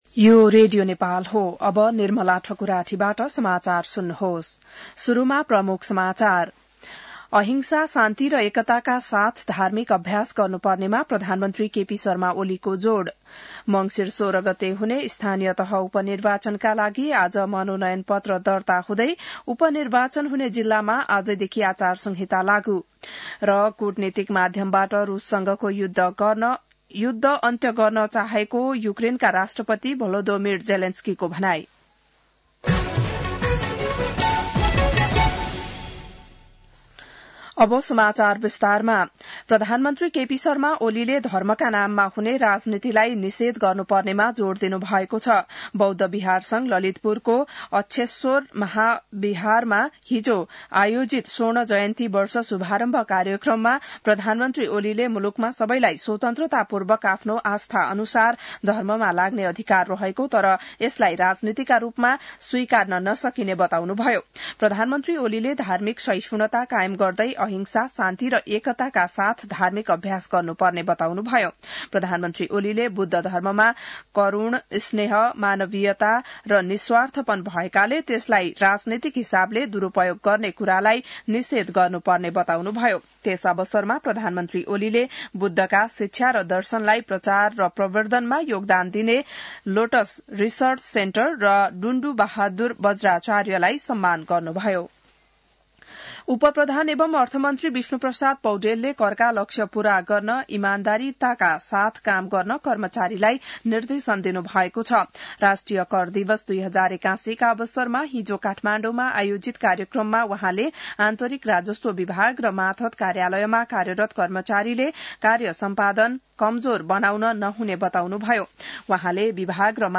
बिहान ९ बजेको नेपाली समाचार : ३ मंसिर , २०८१